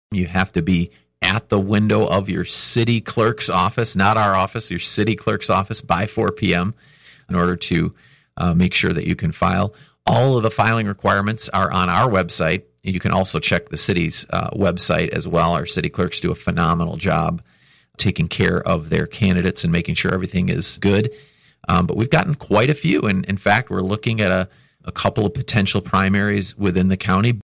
Ottawa County Clerk Justin Roebuck explains what this deadline means.